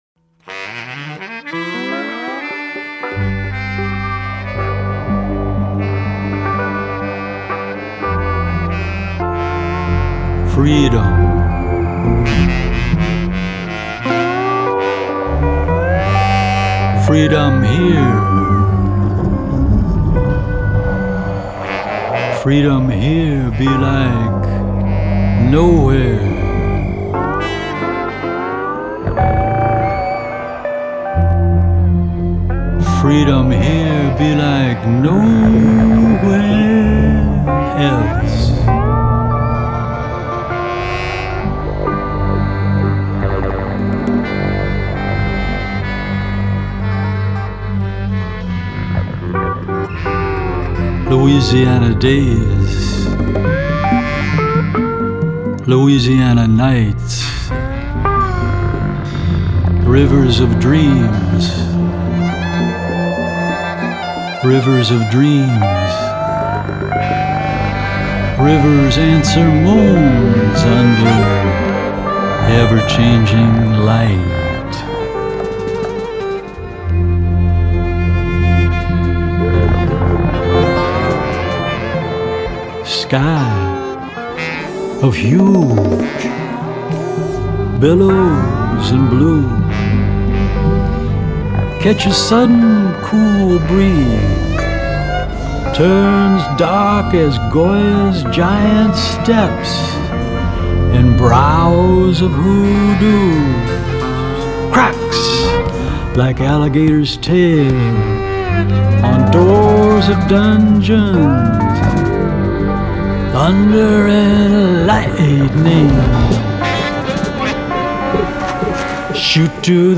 JAZZ, Funk, Gospel, and Poetry